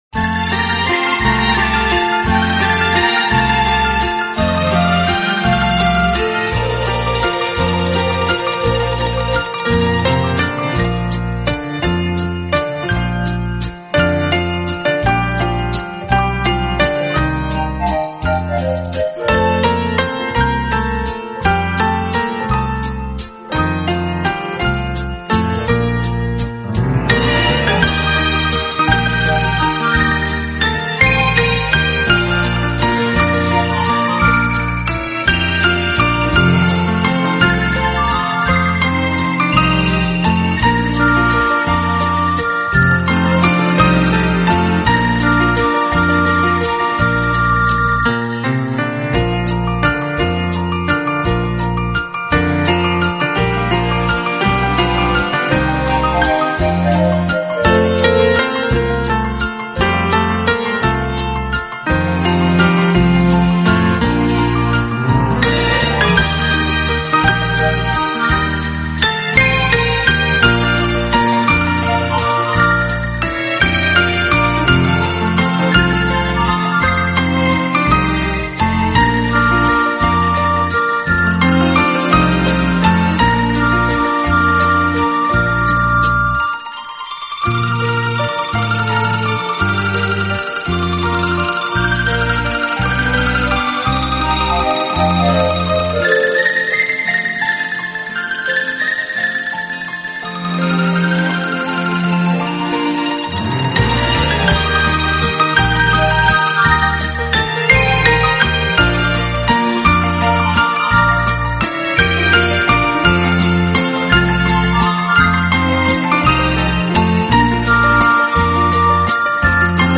整张专辑全部都是以浪漫情调为主题的钢琴合辑，当熟